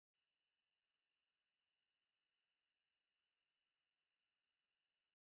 silence.mp3